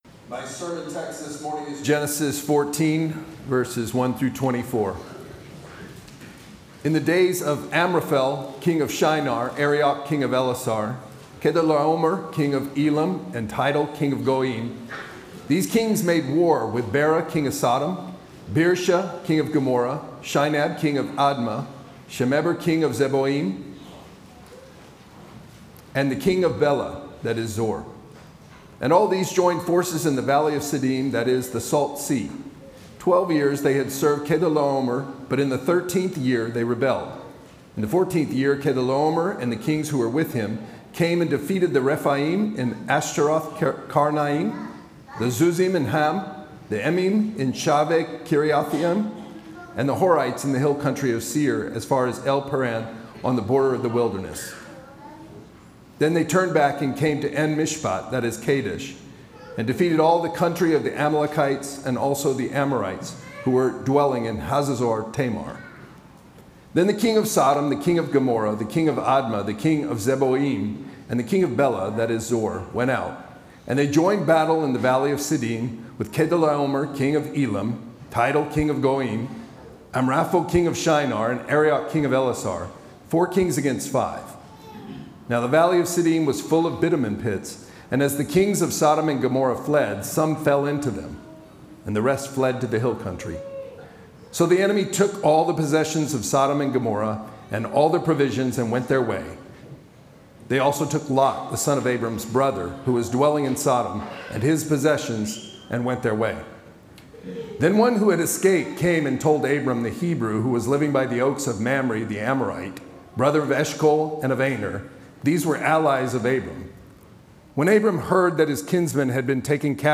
Series: Sermons on Genesis
Service Type: Sunday worship